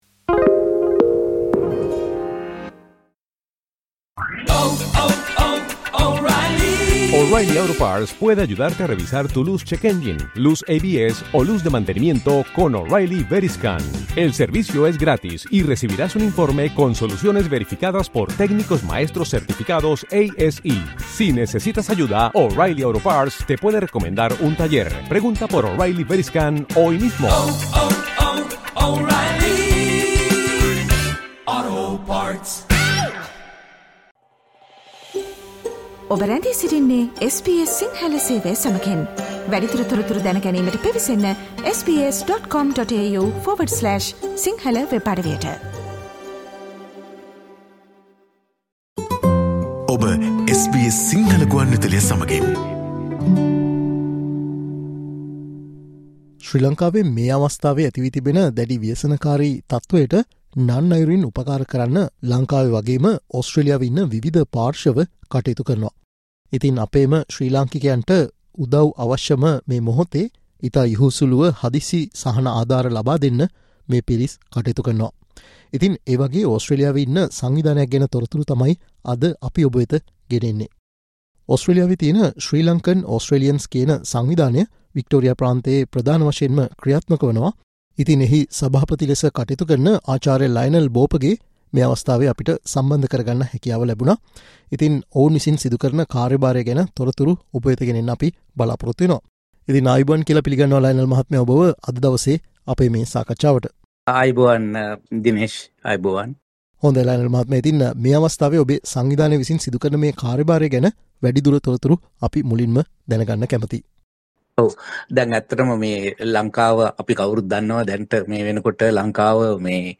SBS සිංහල සේවයේ අප සිදුකල සාකච්චාවට සවන්දෙන්න